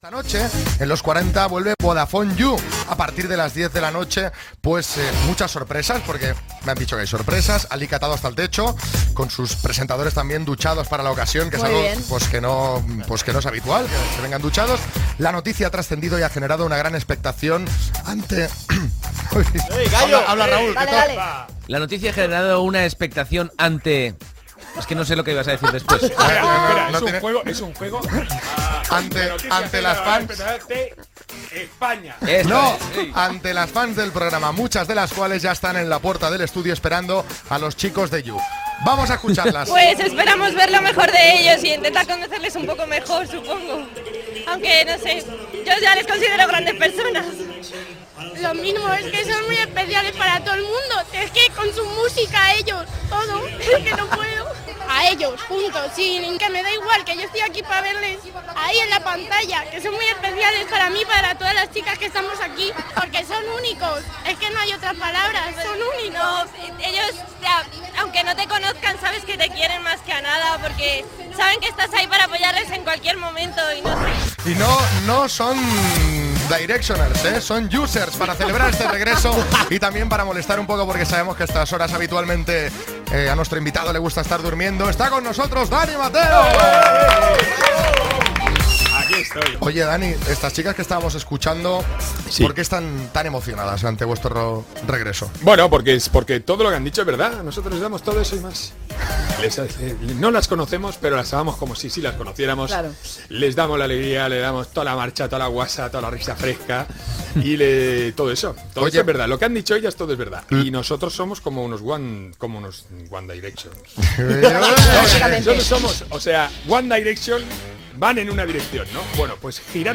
Entrevista a Dani Mateo abans d'iniciar una nova temporada del programa "Yu: No te pierdas nada" de Cadena 40 Principales
Entreteniment